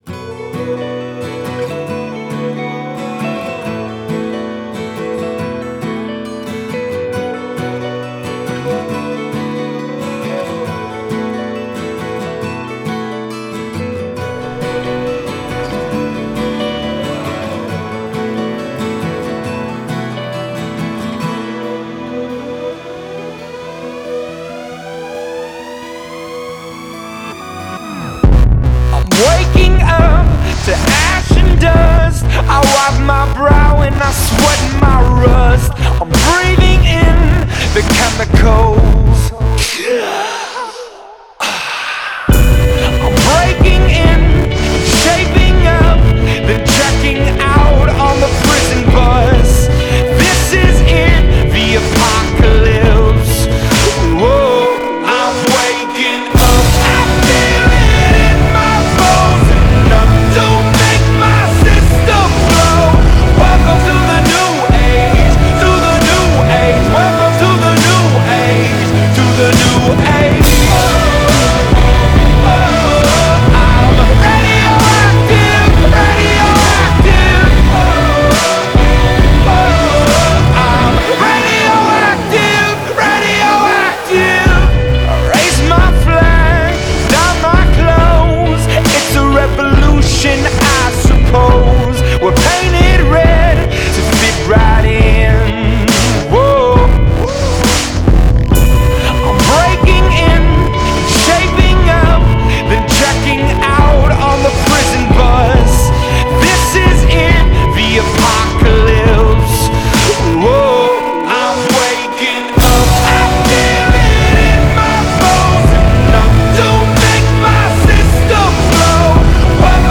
Genre: ’10s Alternative.